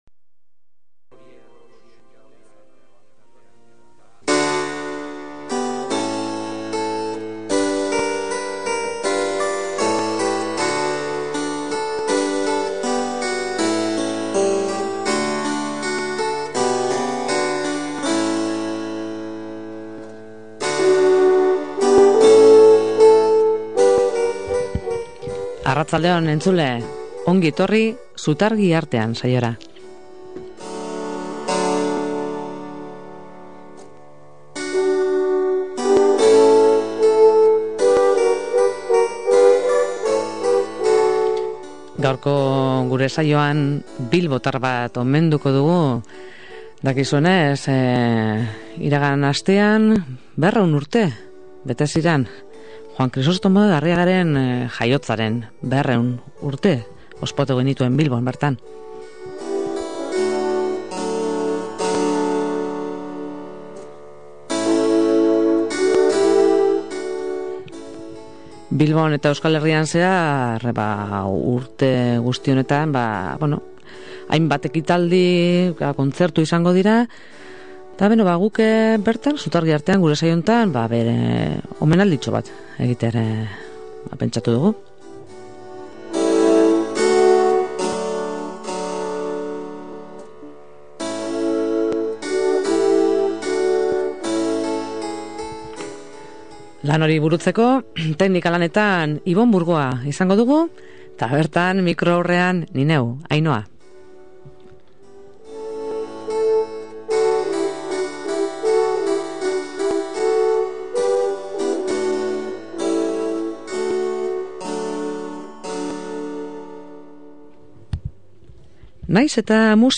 harizko tresnentzako 3. laukotea, harizko tresnentzako 2. laukotearen Andantea (gai aldagaiekin)
Sinfonia Re Maiorrean: Adagio-Allegro Vivace – Andante